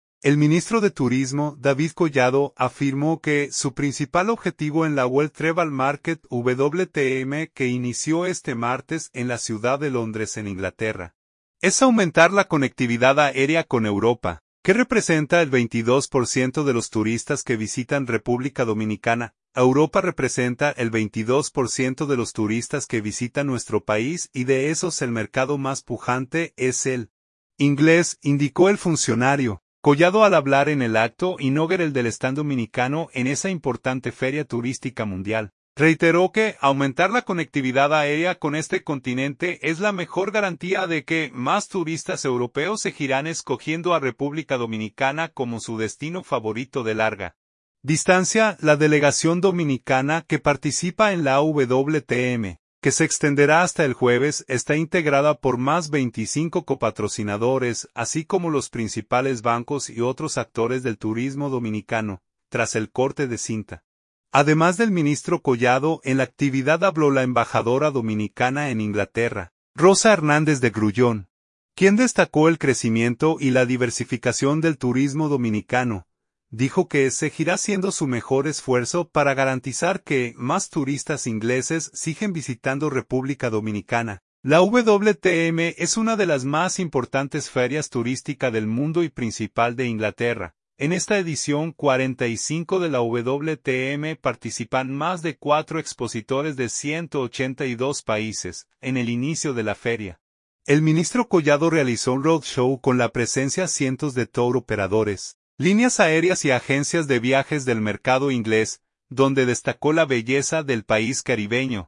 Collado al hablar en el acto inaugural del stand dominicano en esa importante feria turística mundial, reiteró que aumentar la conectividad aérea con este continente es la mejor garantía de que más turistas europeos seguirán escogiendo a República Dominicana como su destino favorito de larga distancia.